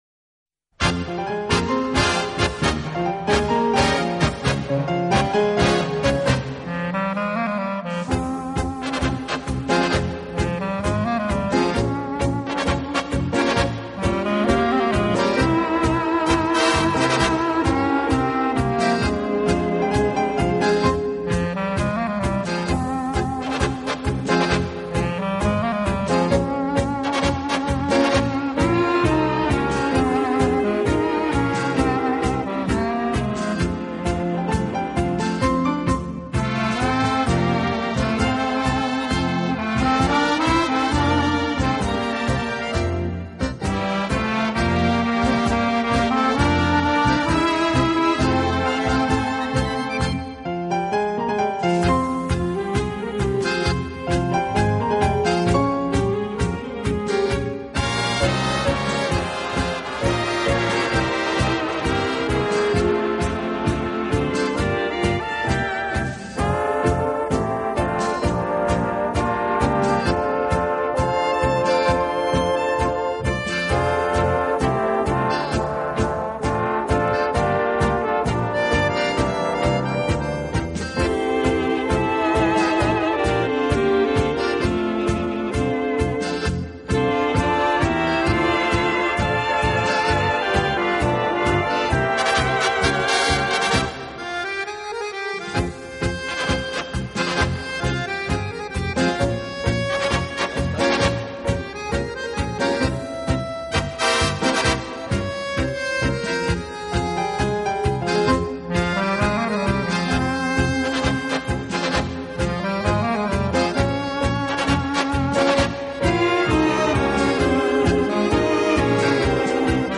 他的乐队以演奏舞